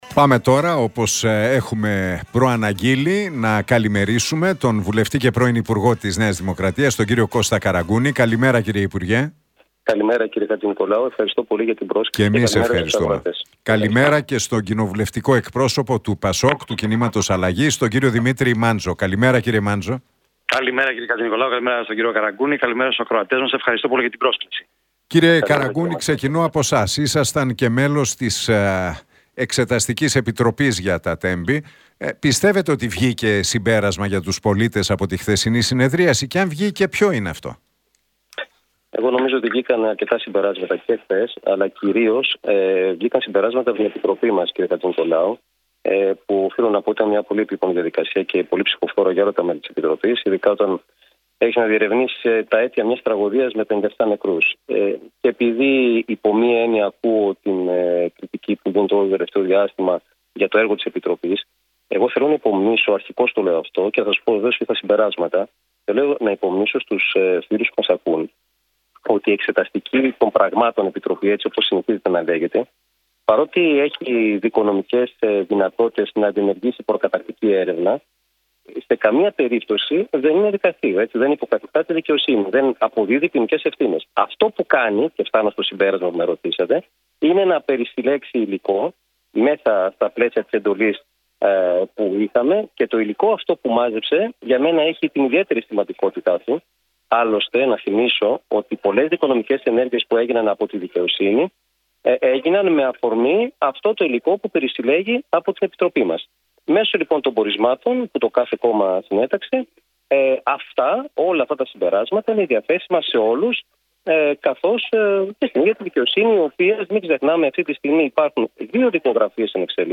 Τα ξίφη τους διασταύρωσαν στον αέρα του Realfm 97,8 σε ένα ραδιοφωνικό debate, στην εκπομπή του Νίκου Χατζηνικολάου ο βουλευτής της ΝΔ, Κώστας Καραγκούνης και ο κοινοβουλευτικός εκπρόσωπος του ΠΑΣΟΚ ΚΙΝΑΛ, Δημήτρης Μάντζος.